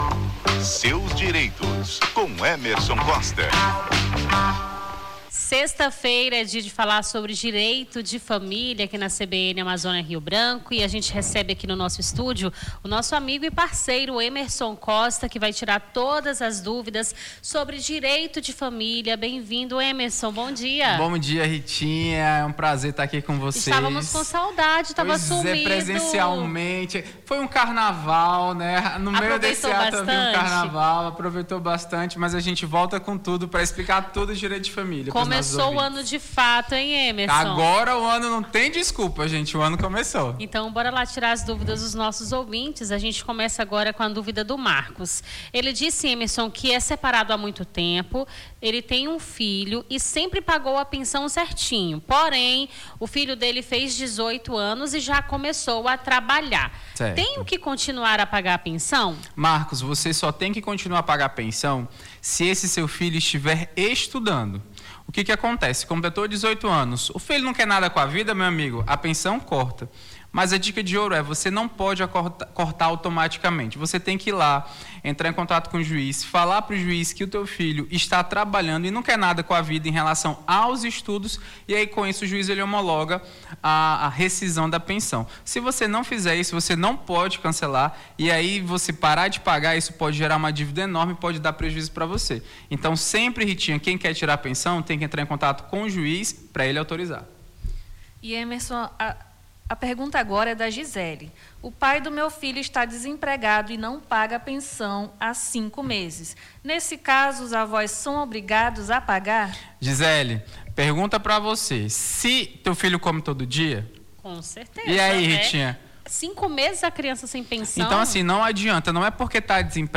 Seus Direitos: advogado esclarece dúvidas sobre direito de família